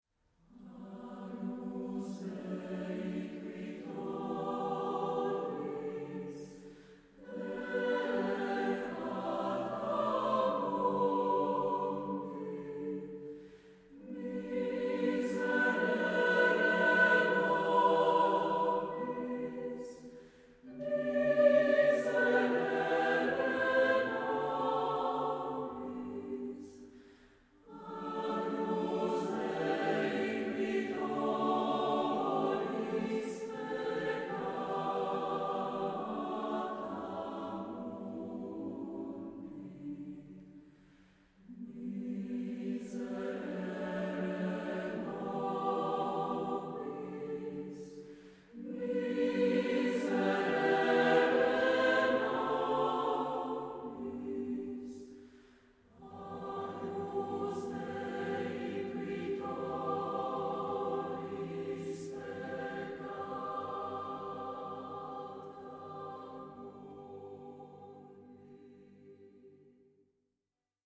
organ
pre sláčikový orchester a organ